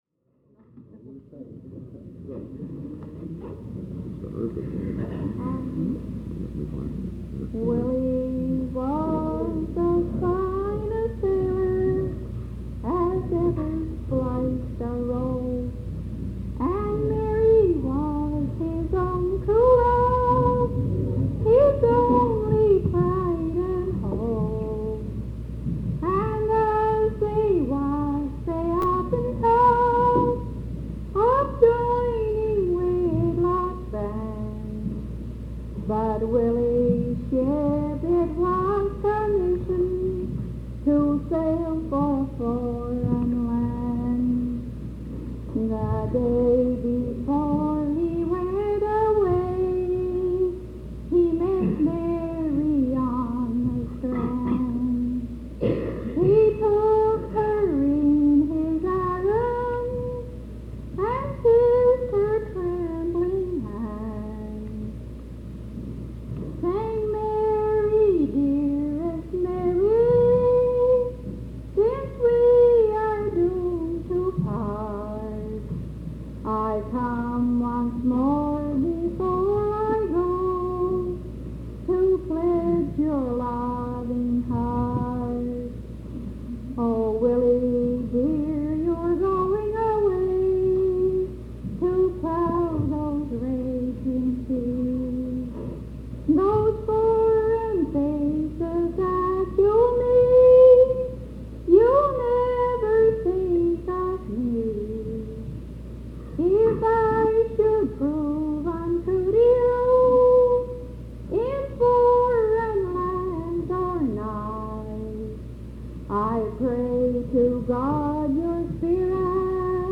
Town: Miramichi, NB